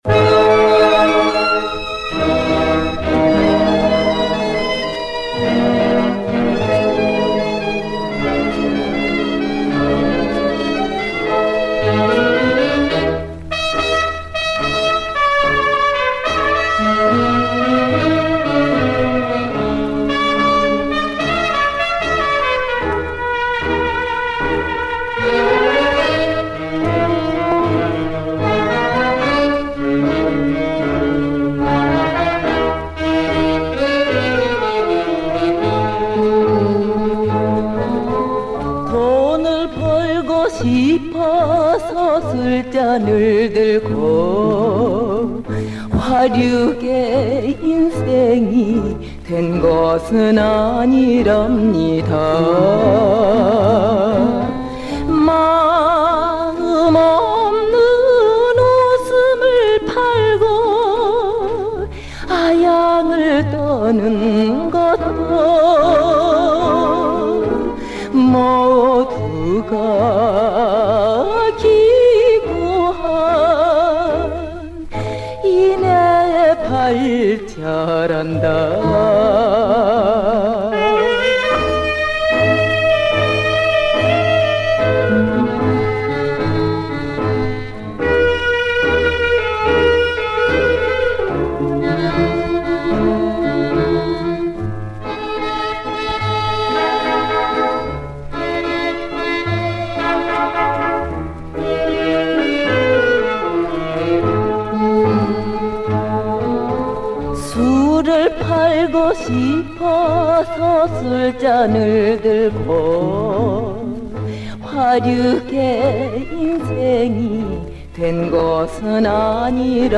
♠그때 그 시절 옛 가요/★50~60년(측음기)